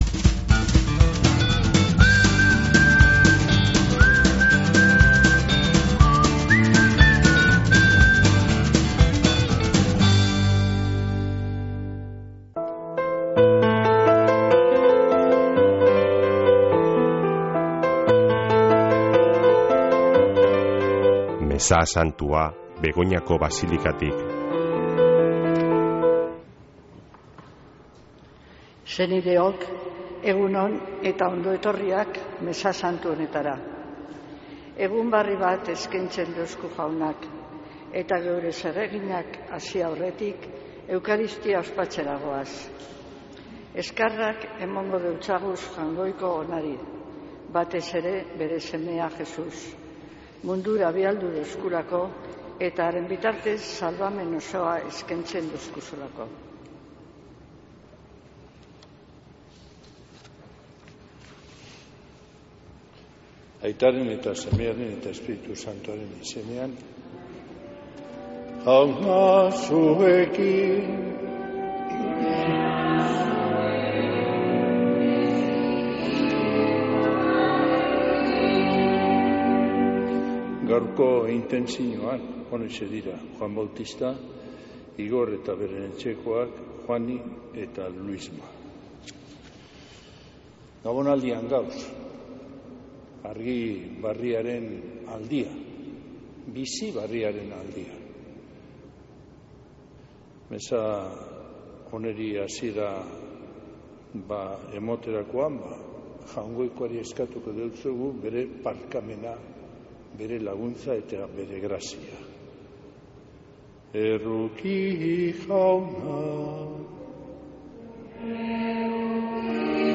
Mezea Begoñako Basilikatik | Bizkaia Irratia
Mezea (25-01-09)